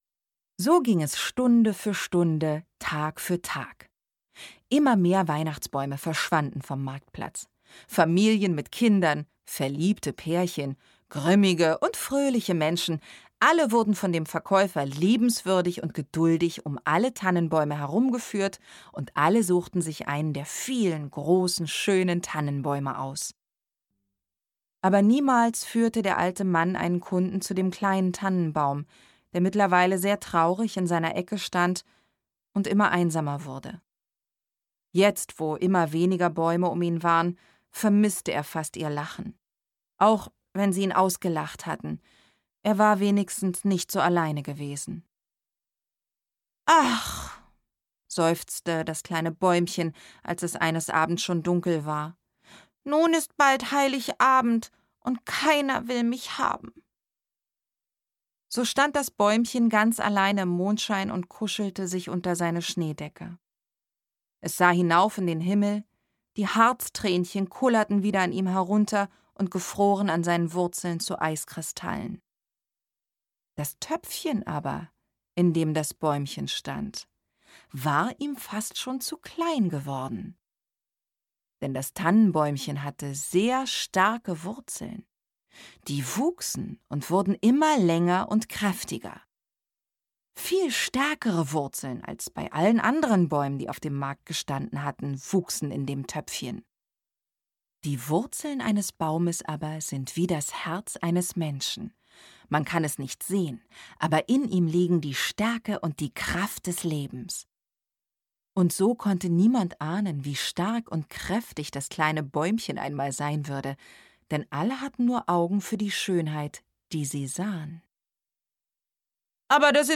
Hörbuch: Die Geschichte vom traurigen Weihnachtsbaum